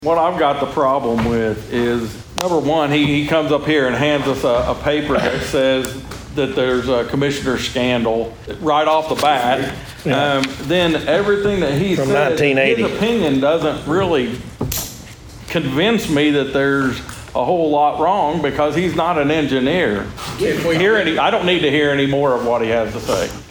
Things Get Testy at Osage Co Commissioner Meeting